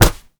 kick_soft_jab_impact_07.wav